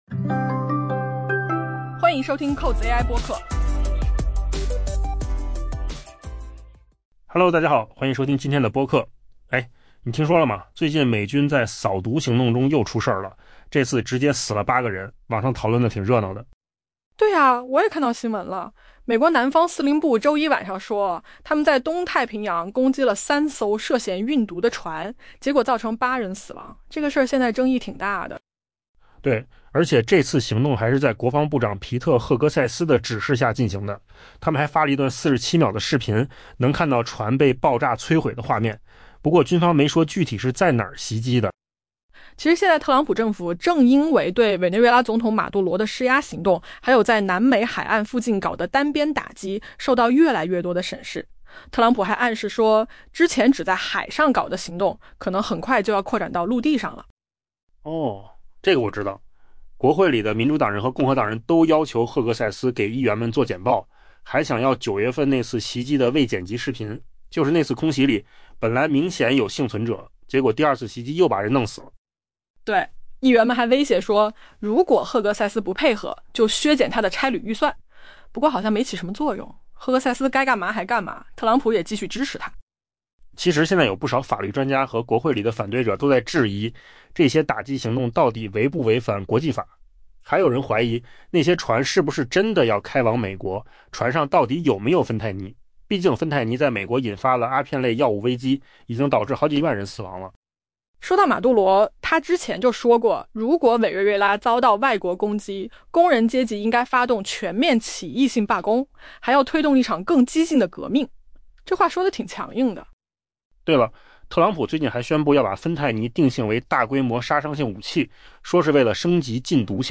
AI播客：换个方式听新闻 下载mp3